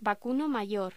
Locución: Vacuno mayor
voz